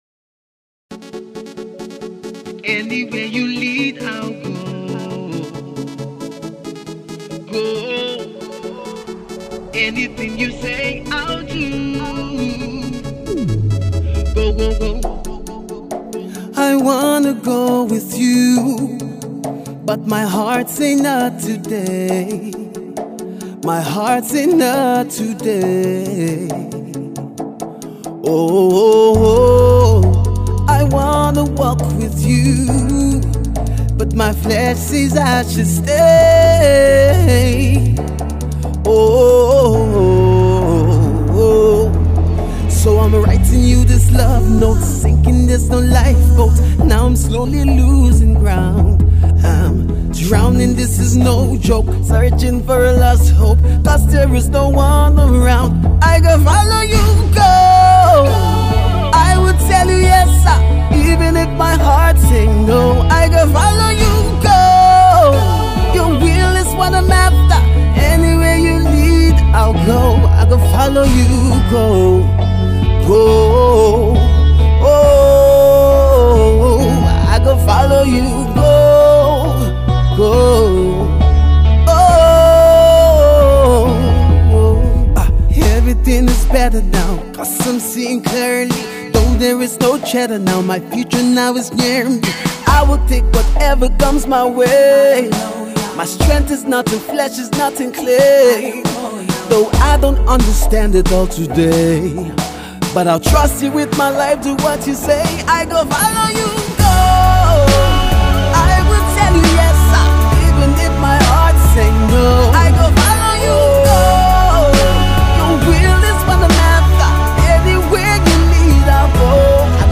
Christian Songs
singer/songwriter
a unique contemporary sound